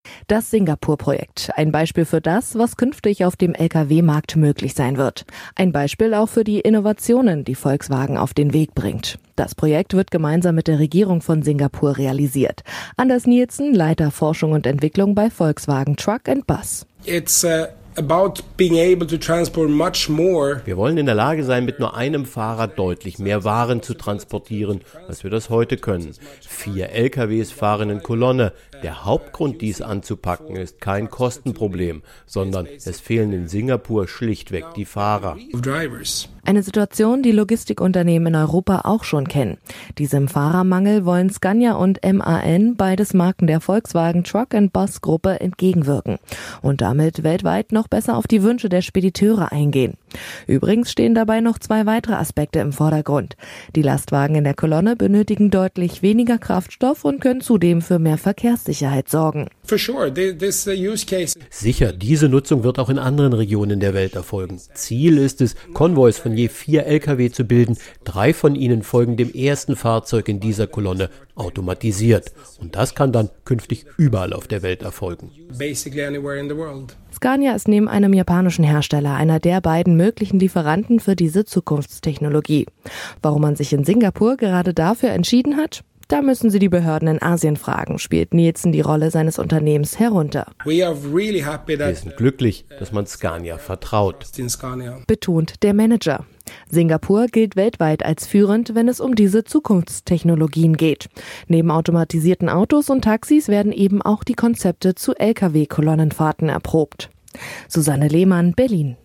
Auto, O-Töne / Radiobeiträge, , , , , ,